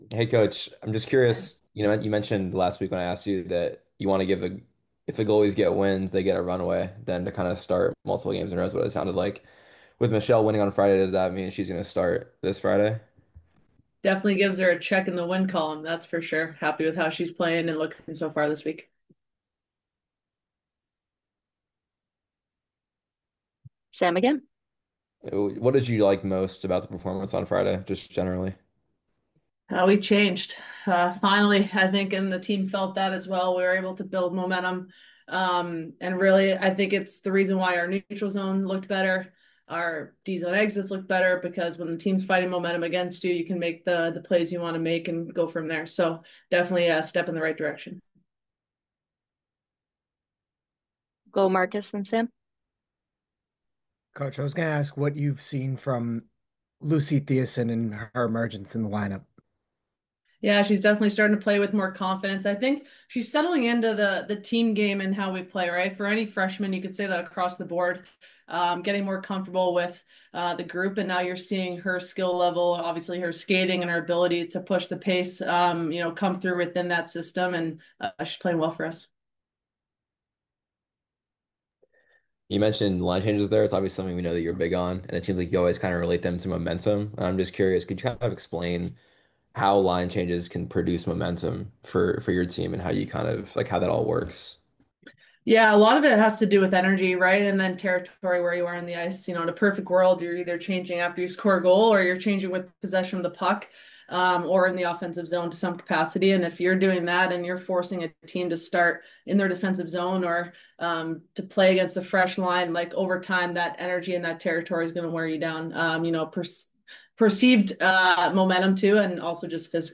Media Call